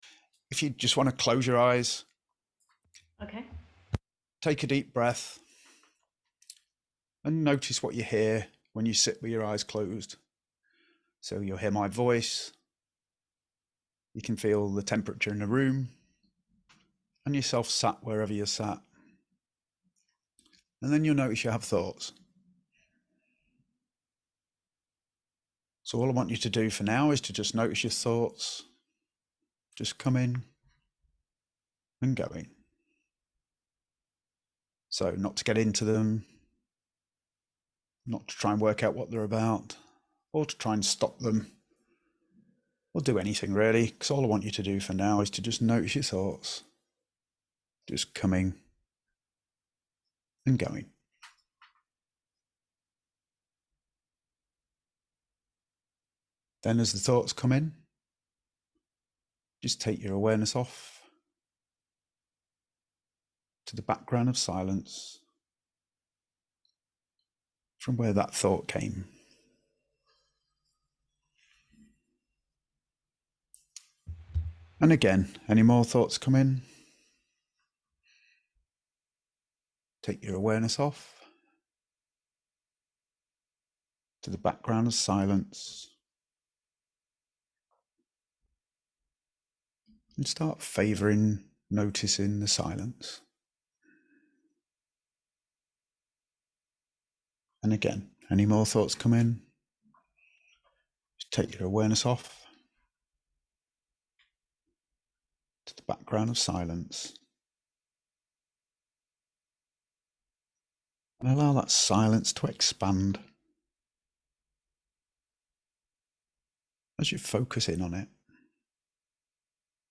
Quieting the Mind Meditation